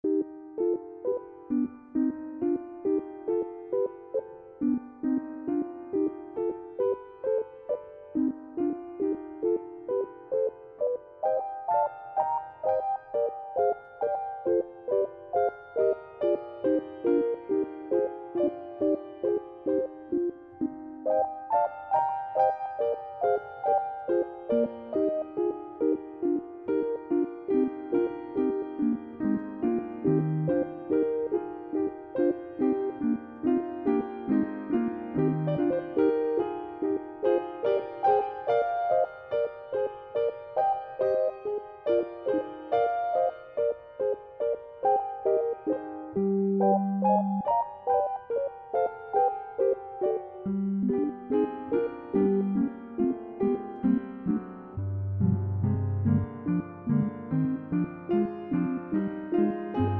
(sinusoidal)   Sonification